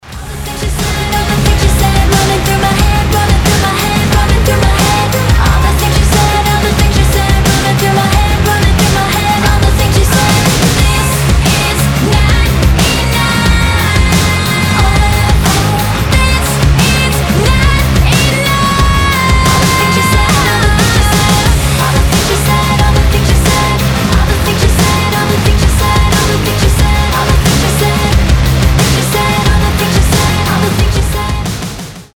• Качество: 320, Stereo
громкие
Cover
Alternative Rock
красивый женский голос